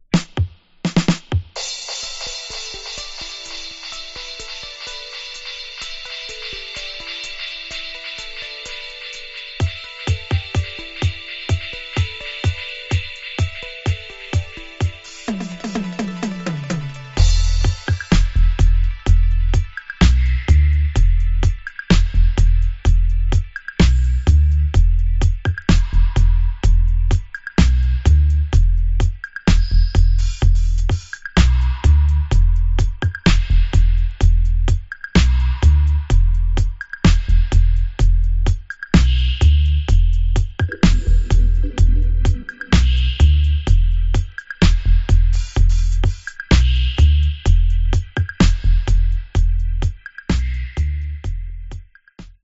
Genre: Dub Reggae.